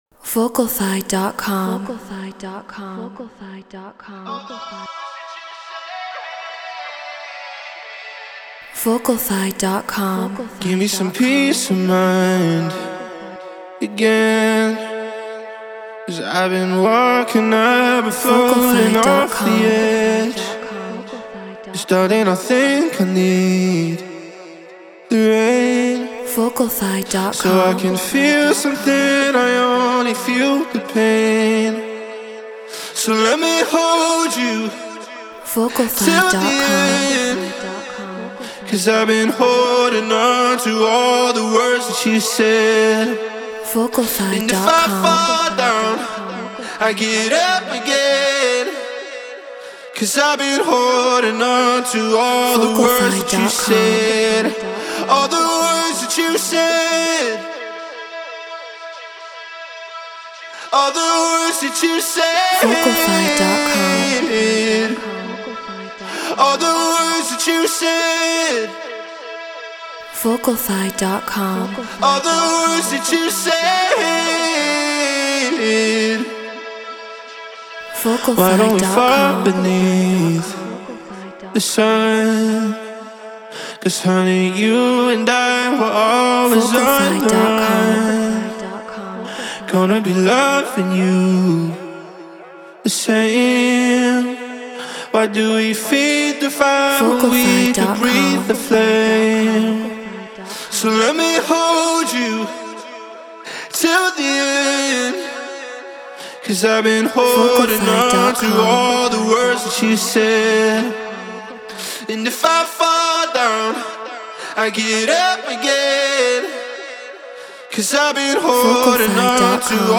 Drum & Bass 170 BPM Amaj
Shure SM7B Apollo Twin X Logic Pro Treated Room